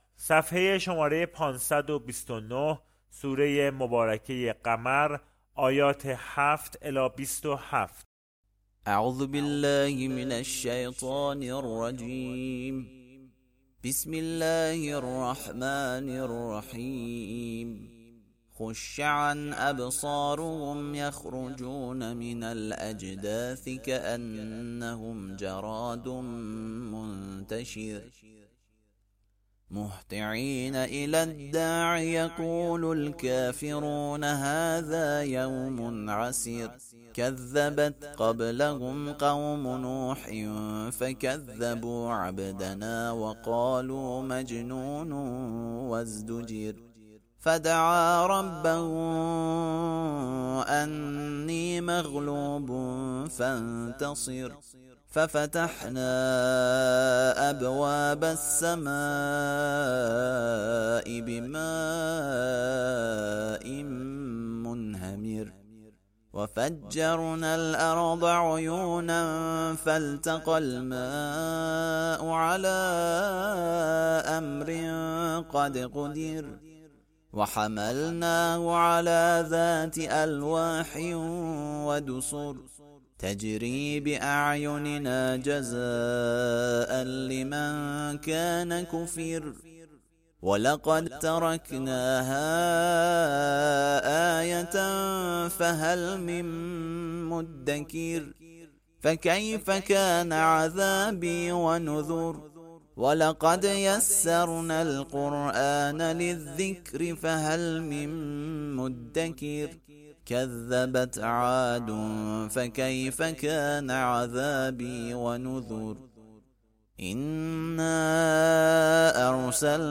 ترتیل صفحه ۵۲۹ سوره مبارکه قمر (جزء بیست و هفتم)
ترتیل سوره(قمر)